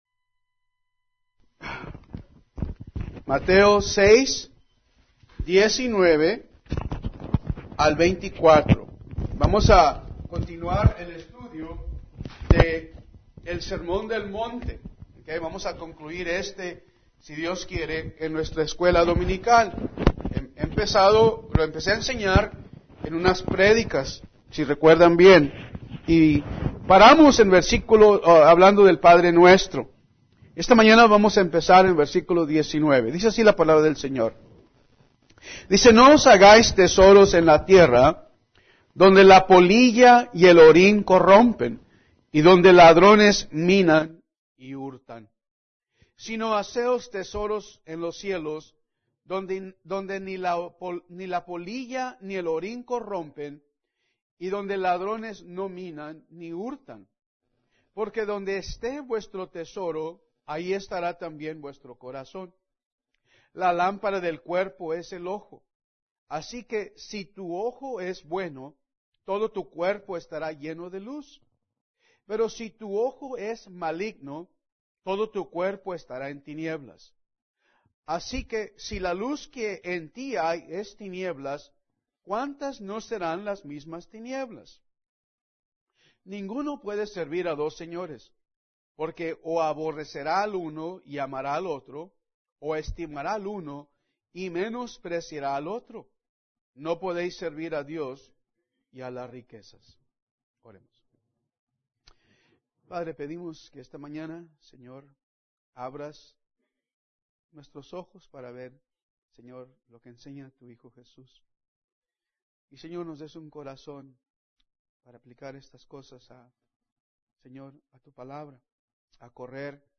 Escuela dominical – Mateo